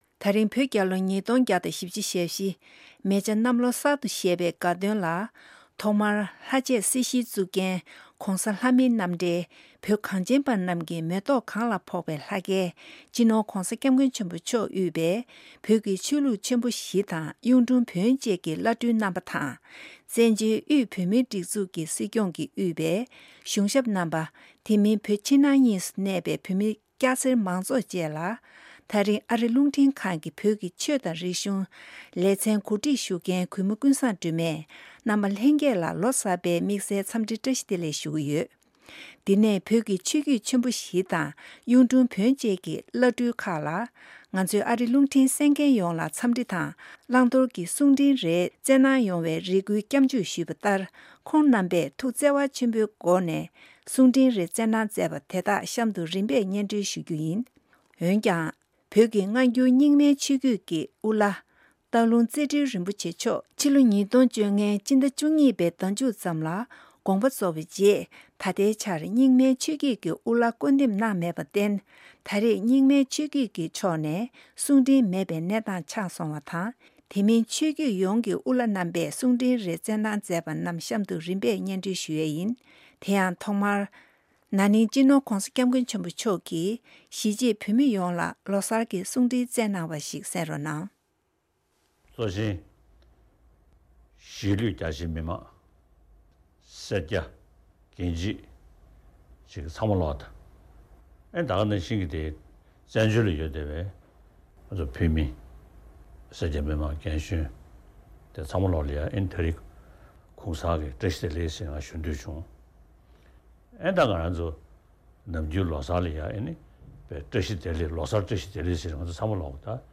བོད་ཀྱི ཆོས་བརྒྱུད་ཆེན་པོ་བཞི་དང་ གཡུང་དྲུང་བོན་བཅས་ཀྱི་དབུ་བླ་རྣམས་པས་ལོ་གསར་གསུང་འཕྲིན།
བོད་རྒྱལ་ལོ་༢༡༤༤མེ་བྱ་གནམ་ལོ་གསར་དུ་བཞད་པའི་དགའ་སྟོན་ལ་བོད་ཀྱི་ཆོས་ལུགས་ཆེན་པོ་བཞི་དང་ གཡུང་དྲུང་བོན་བཅས་ཀྱི་དབུ་བླ་རྣམས་ཀྱིས་འཚམས་འདྲི་དང་ གསུང་འཕྲིན་རེར་བསྩལ་གནང་མཛད་པ་ཞིག་གསན་རོགས་གནང་།